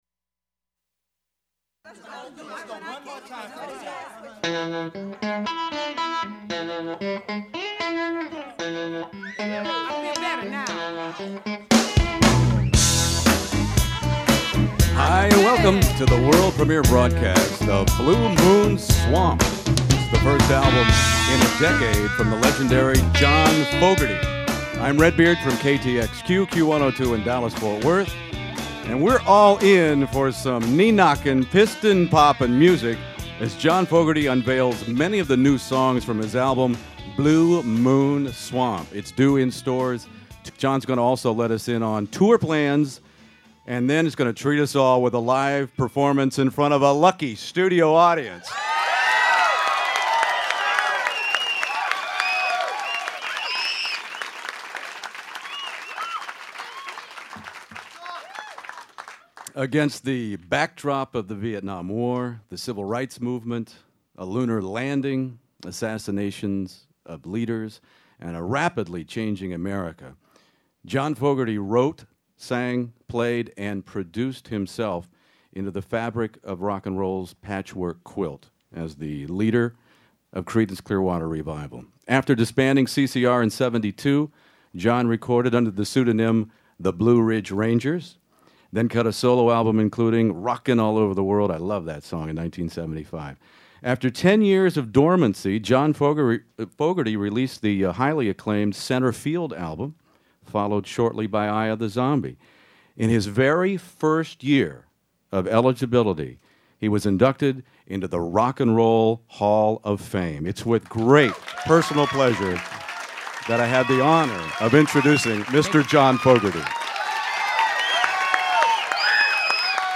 And Fogerty was willing to talk about all of it.